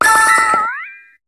Cri de Ceriflor dans Pokémon HOME.